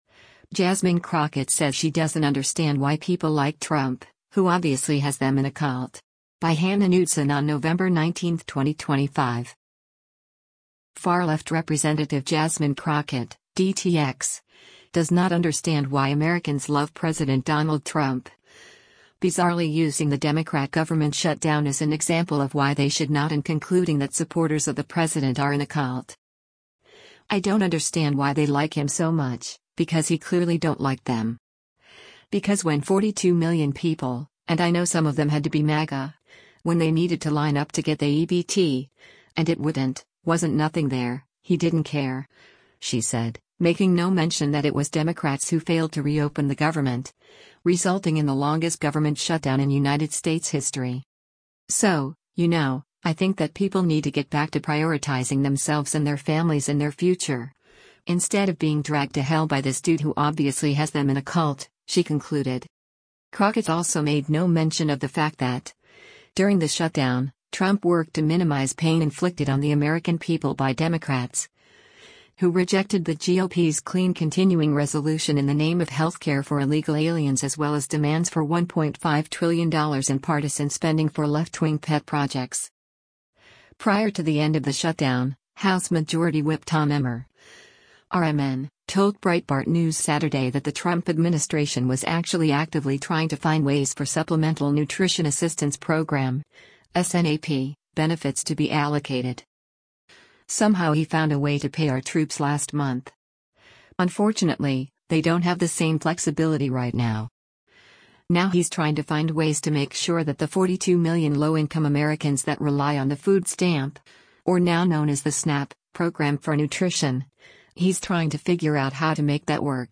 Congresswoman Jasmine Crockett speaks during The Color of Conversation during 2025 Martha&